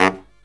LOHITSAX12-L.wav